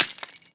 vial_smash.WAV